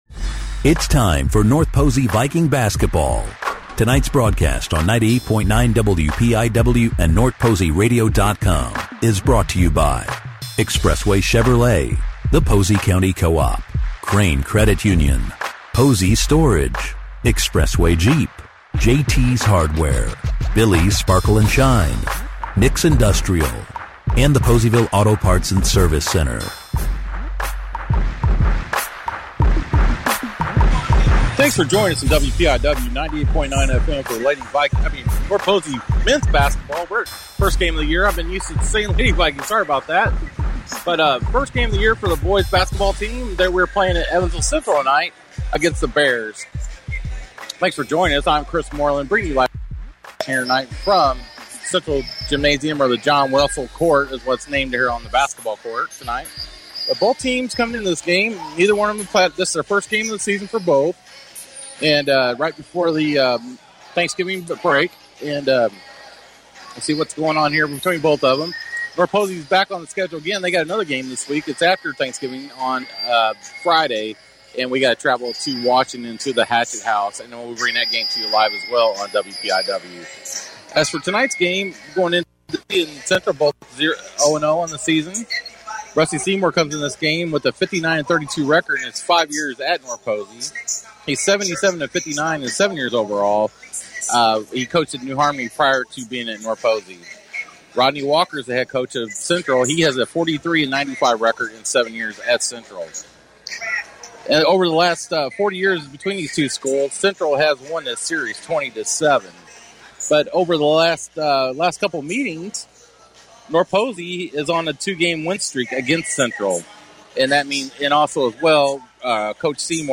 You can listen to the game broadcast below.